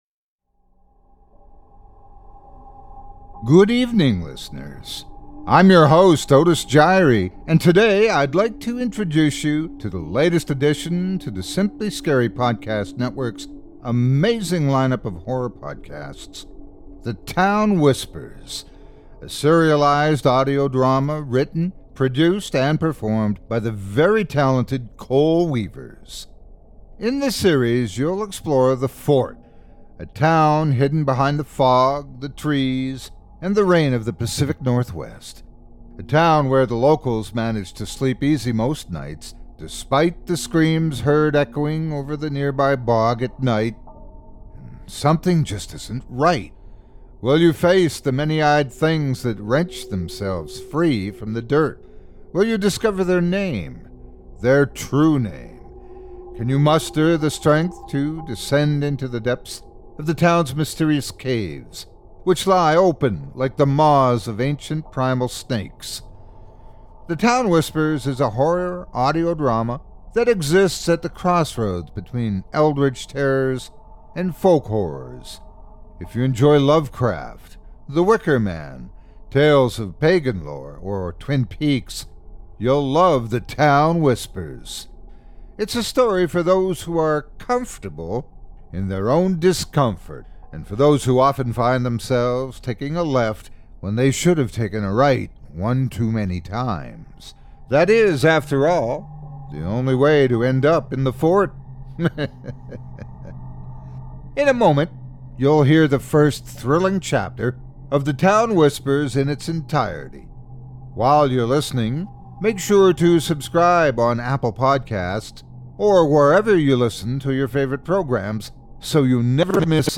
The Town Whispers is a horror audio drama that exists at the crossroads between Eldritch terrors, and folk horrors.